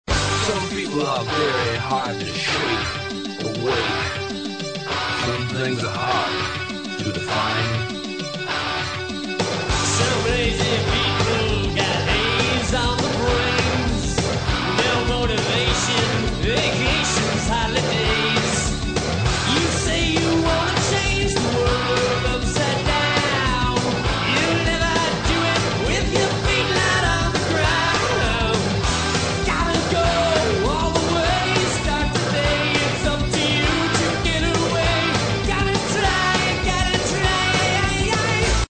It's an inspirational rocker to "get things started."